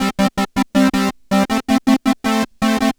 Track 16 - Arp 04.wav